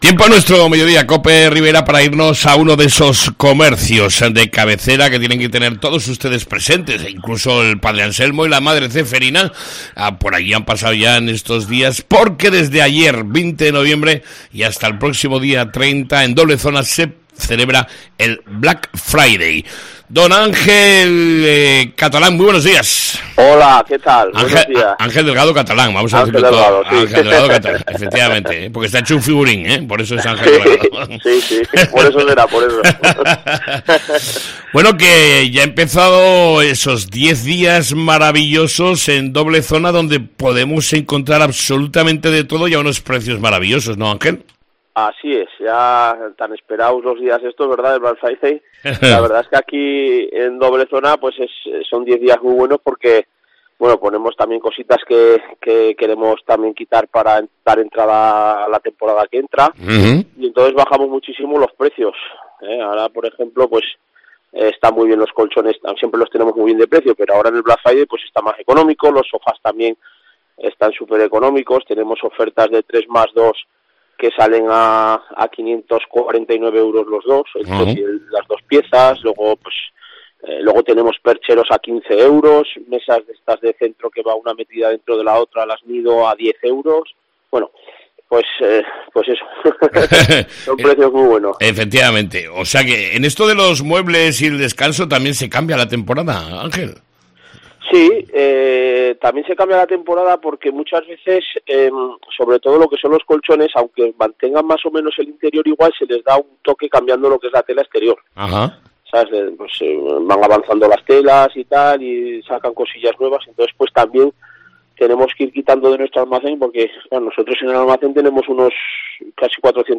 ENTREVISTA CON EL COMERCIANTE LOCAL